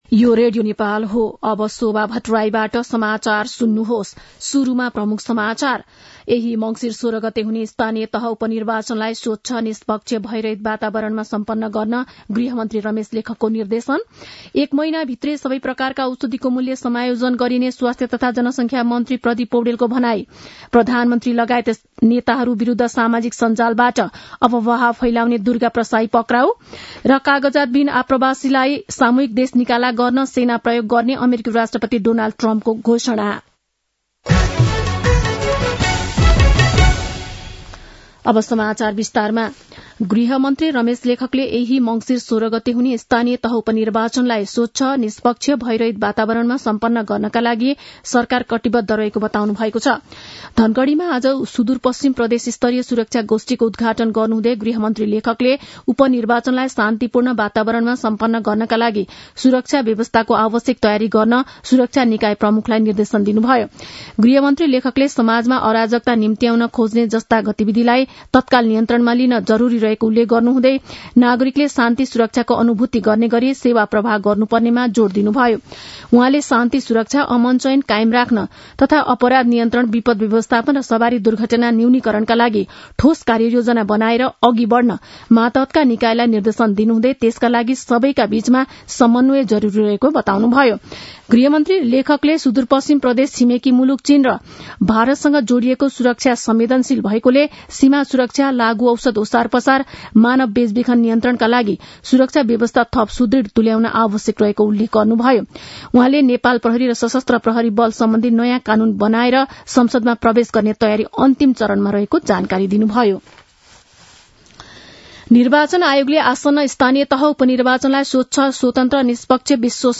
दिउँसो ३ बजेको नेपाली समाचार : ५ मंसिर , २०८१
3-pm-nepali-news-1-3.mp3